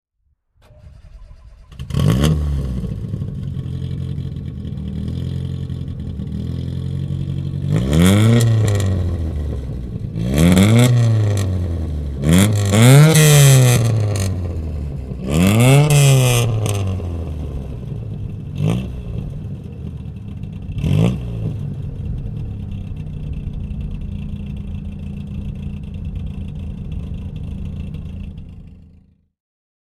Fiat Abarth 124 Rally Gruppe 4 (1973) - Starten und Leerlauf